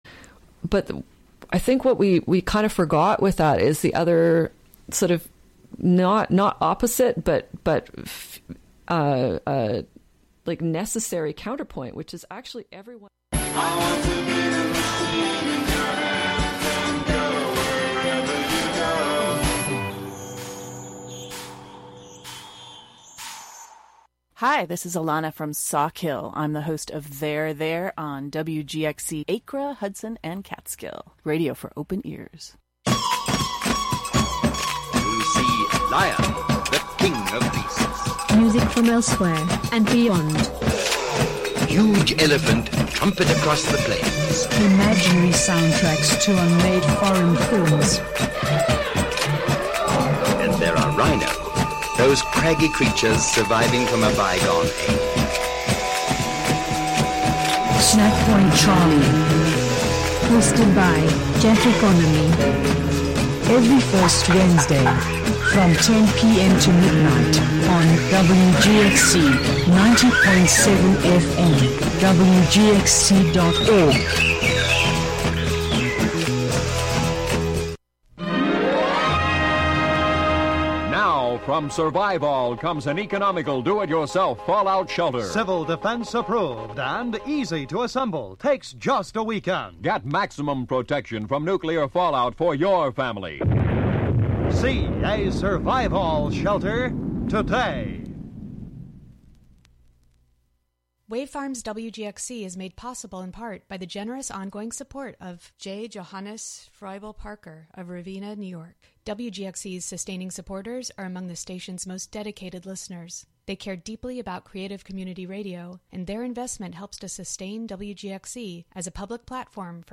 Contributions from many WGXC programmers.
The "WGXC Morning Show" features local news, interviews with community leaders and personalities, a rundown of local and regional events, weather updates, and more about and for the community. The show is a place for a community conversation about issues, with music, and more. Saturday the emphasis is more on radio art, and art on the radio.